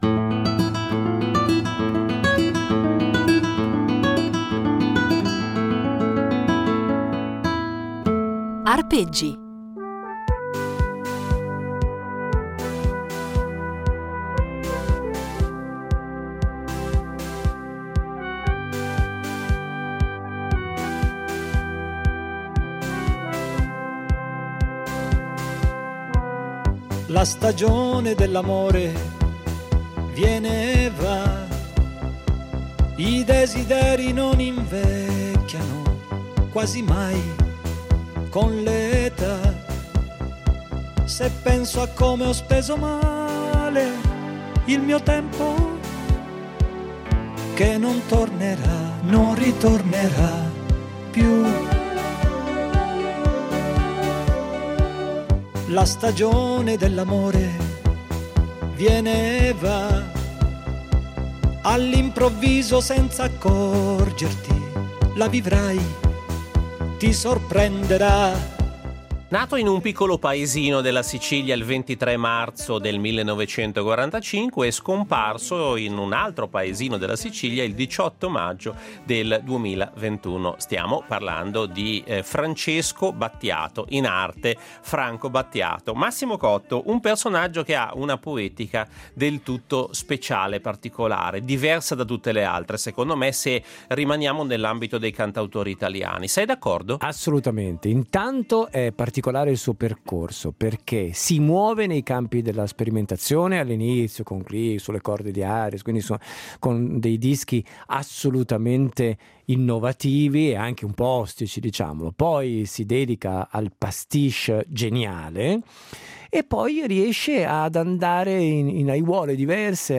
Il giornalista, disc jockey e scrittore piemontese Massimo Cotto ha avuto la fortuna, durante la sua lunga carriera, di incontrare e intervistare un gran numero di nomi illustri della musica italiana e internazionale.